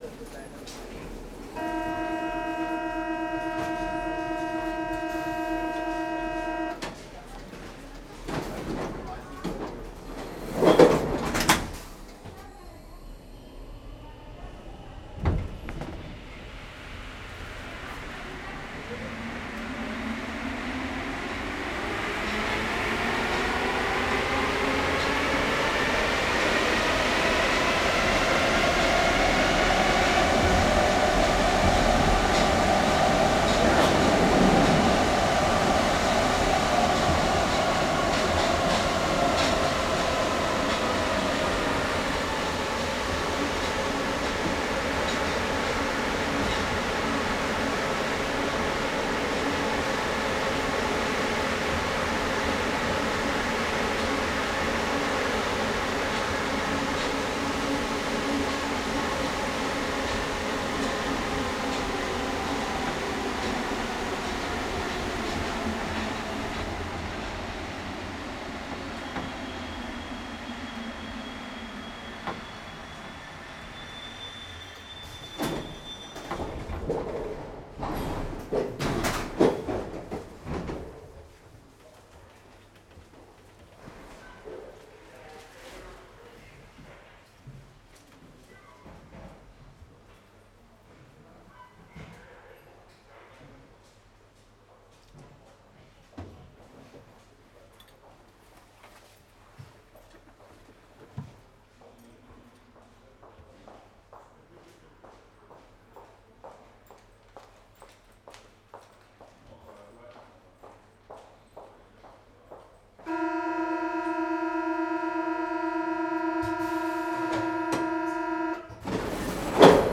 subway.R.wav